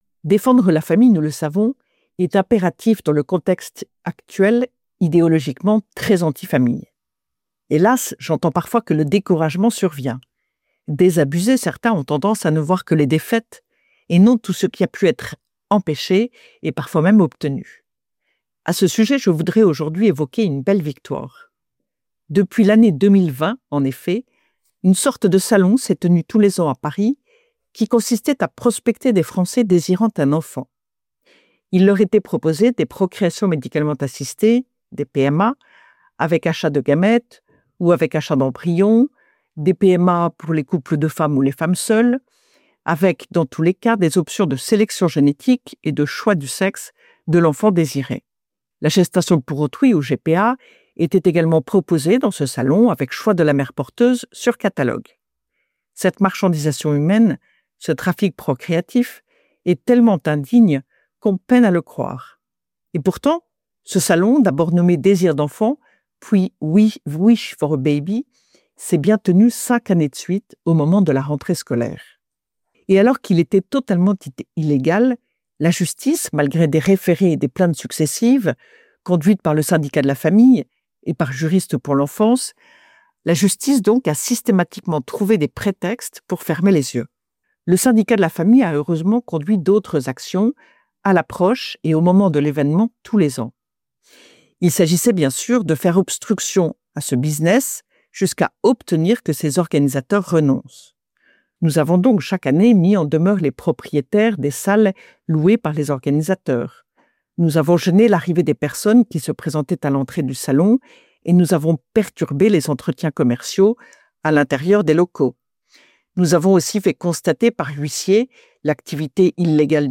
« Esprit de Famille » : Retrouvez chaque semaine la chronique de Ludovine de La Rochère, diffusée le samedi sur Radio Espérance, pour connaître et comprendre, en 3 minutes, l’essentiel de l’actualité qui concerne la famille.